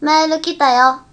『メール来たよ』の浜崎ヴォイスが。